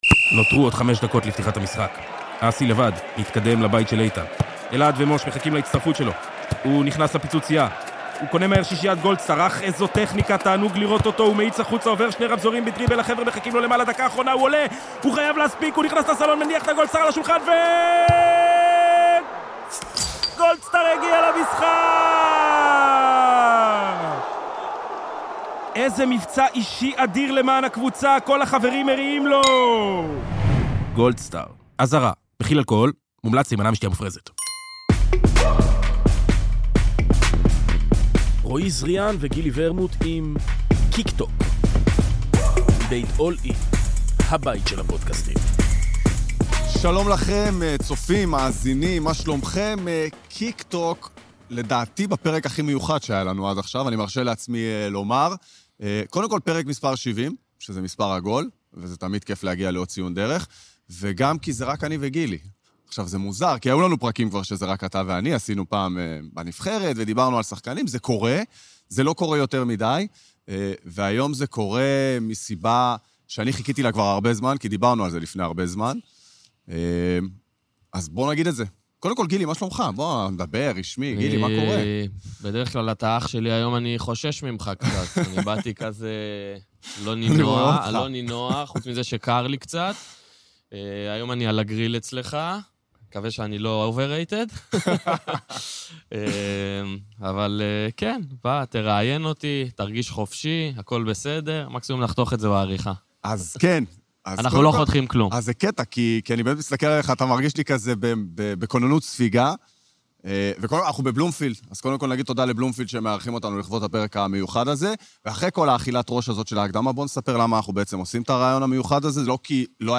קיקטוק - עשור למעבר, פרק ספיישל מבלומפילד, ראיון עם גילי ורמוט | #70